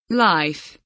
life kelimesinin anlamı, resimli anlatımı ve sesli okunuşu